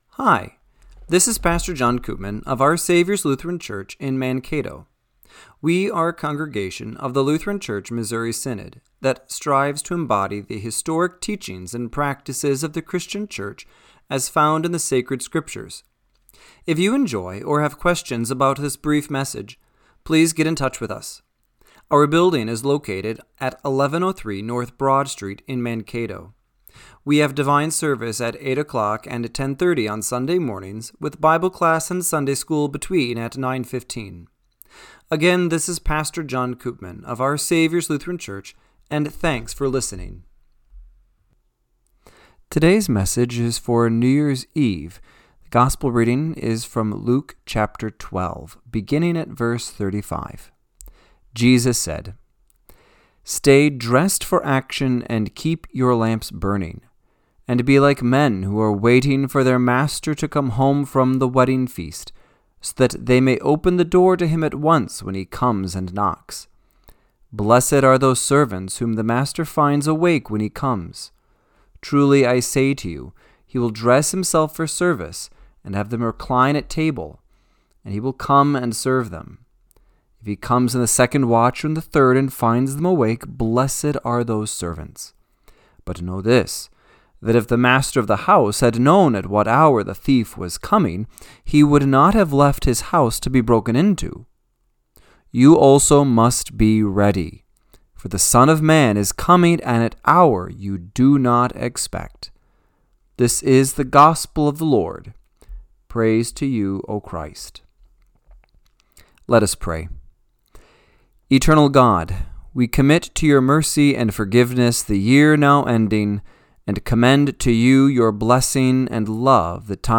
Radio-Matins-1-4-26.mp3